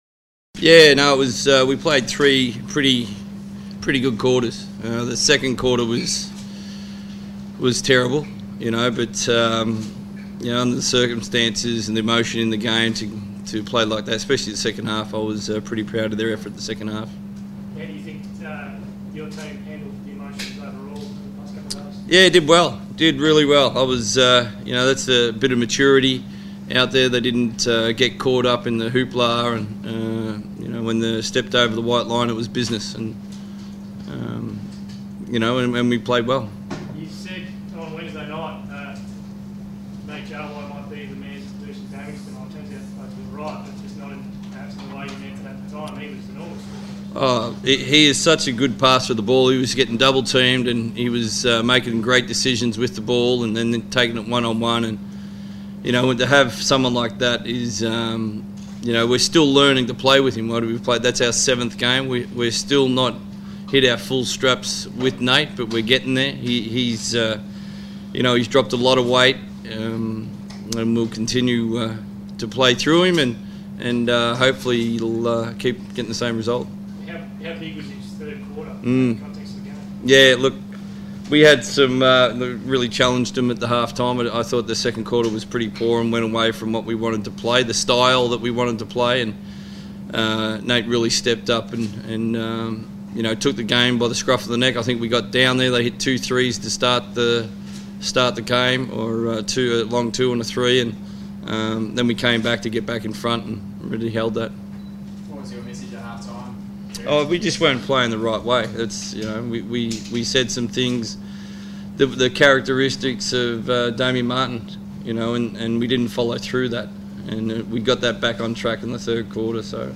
speak to the media following the Perth WIldcats win over the Townsville Crocodiles.